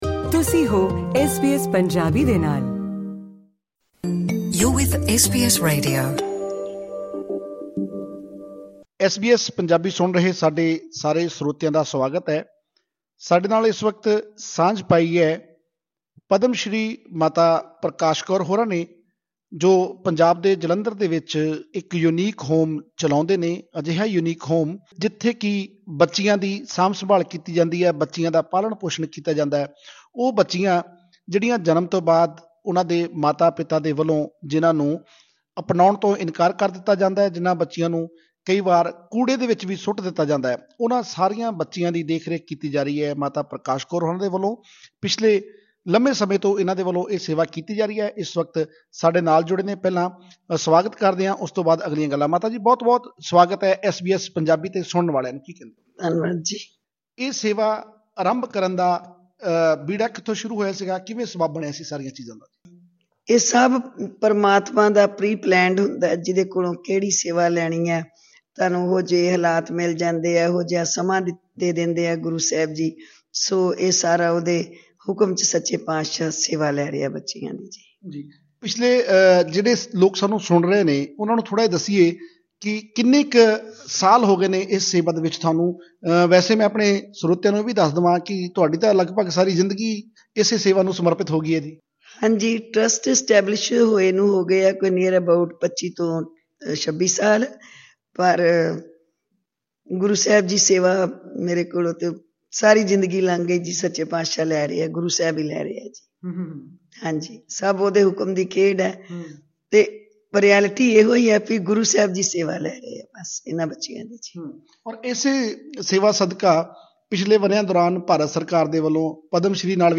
ਐੱਸਬੀਐੱਸ ਨਾਲ ਗੱਲਬਾਤ ਕਰਦਿਆਂ